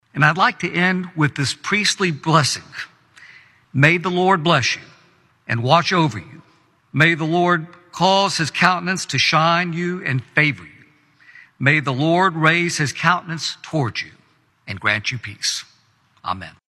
A large crowd attended the 73rd annual National Prayer Breakfast in Washington D.C. last week.
After reading scripture from Exodus Chapter 15, Congressman Kustoff closed with a prayer to attendees.(AUDIO)